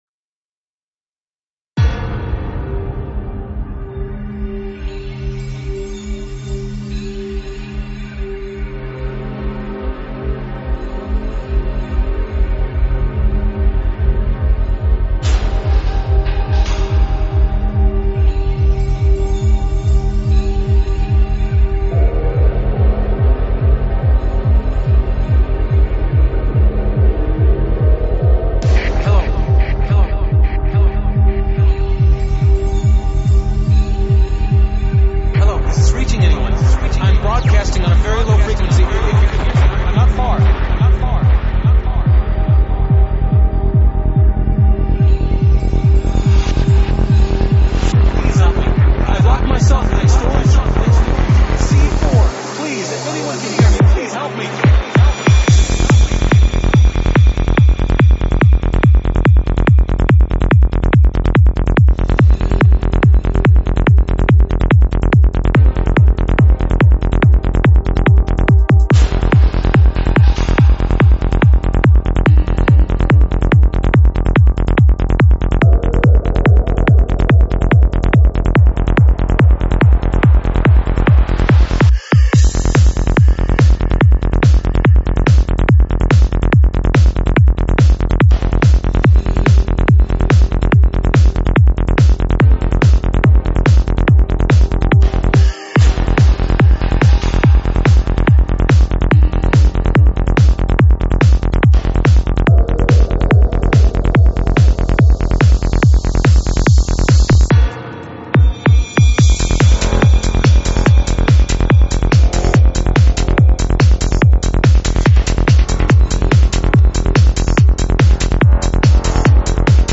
Стиль: Psytrance